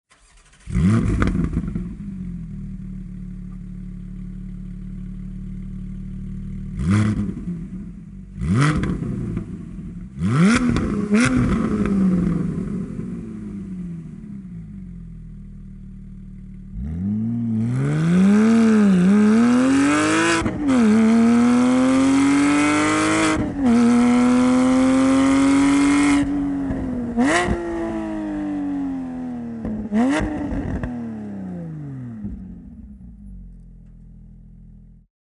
ASÍ SONARÁ EN TU PORSCHE.
Con la fusión de estos dos productos de última generación, el sonido adquiere un verdadero carácter de carreras con un tono deportivo y agradable de alta frecuencia, totalmente acorde con el rendimiento del vehículo.
Slip-On-Race-Line-Link-Pipe-Set-Porsche-718-Cayman-GT4-5.mp3